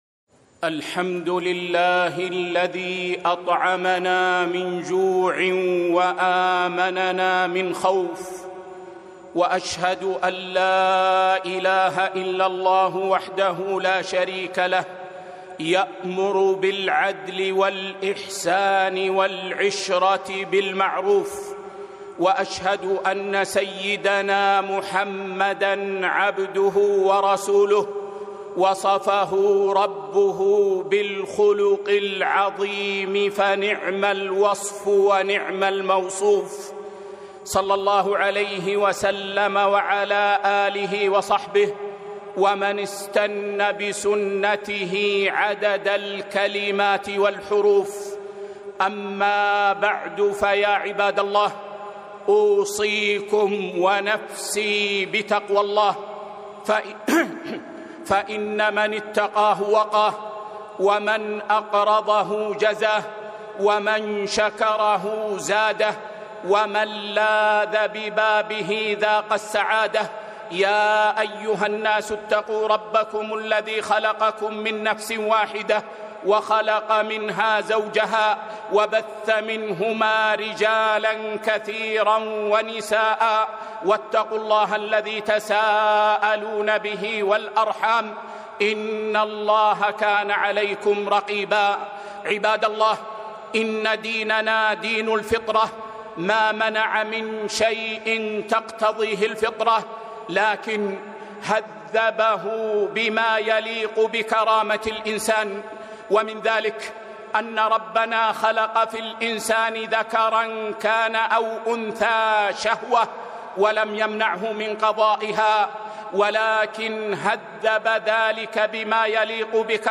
خطبة - وعاشروهن بالمعروف